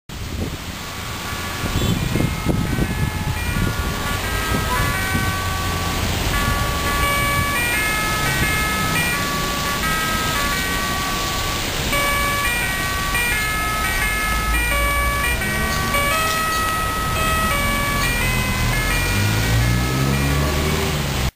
雨の中で、収録中に車が通るたびにシャーという音が入るため実際行きたくないながらも行きましたが、結構２１号以外がありました。
福岡市は、故郷の空を無理やり１曲手前で終わらせているような気がします。